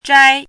chinese-voice - 汉字语音库
zhai1.mp3